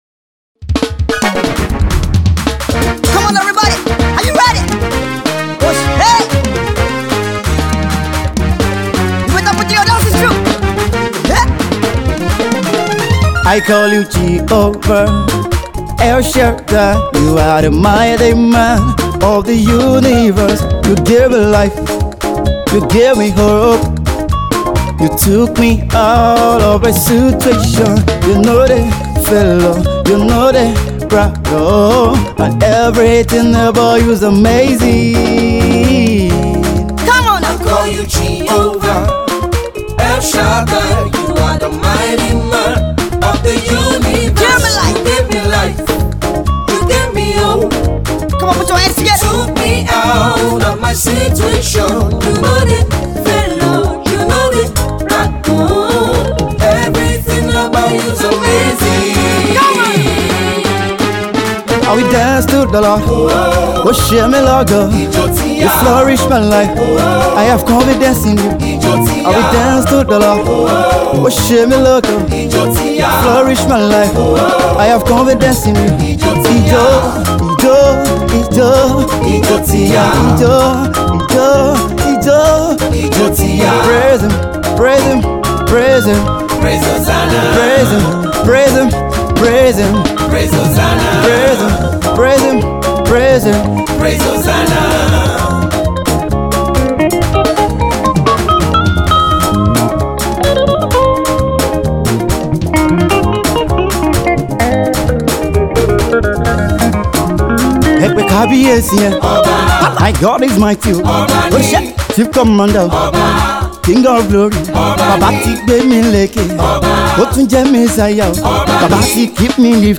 Nigerian gospel minister